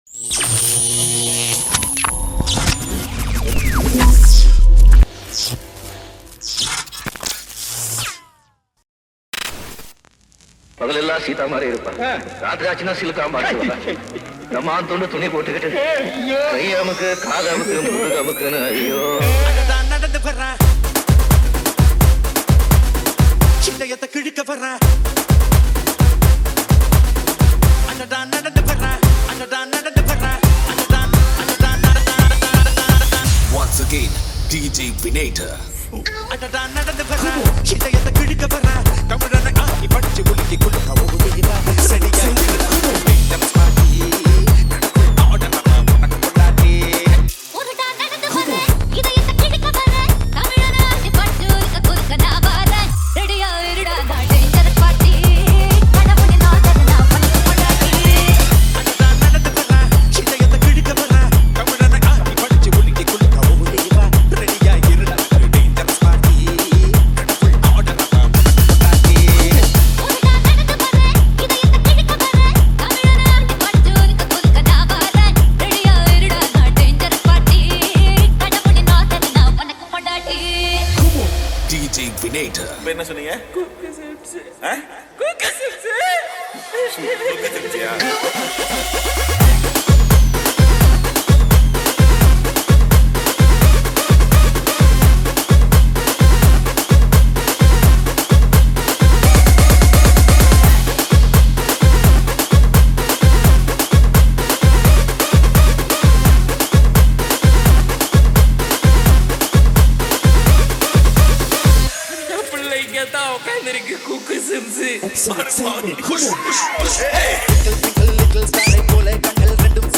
ALL TAMIL LOVE DJ REMIX